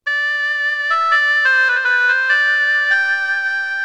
goboi.wav